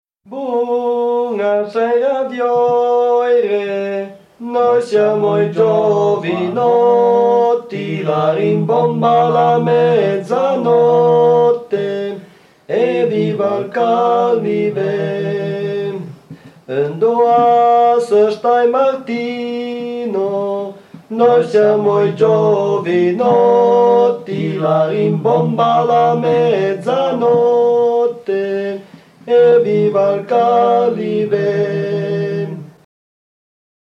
La canzone del cappello / [registrata a Villar Perosa (TO), nel 1973